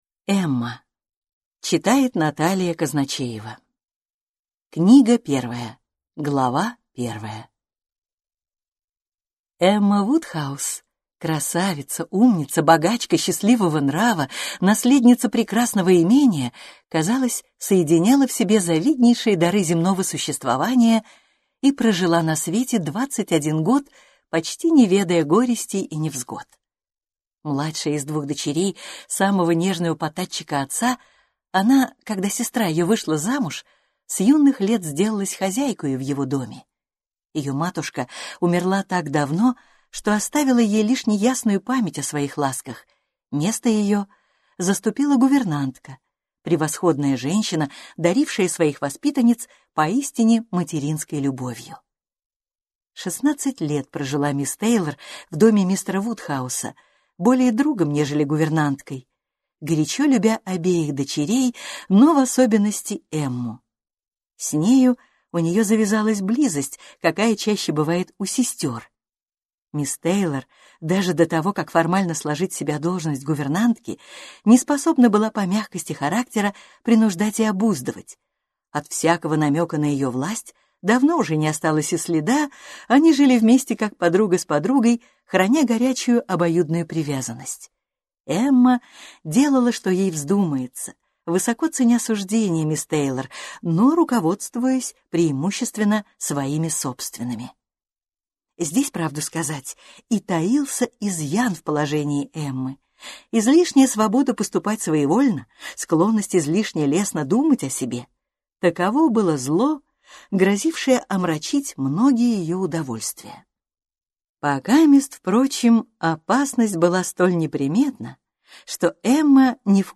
Аудиокнига Эмма | Библиотека аудиокниг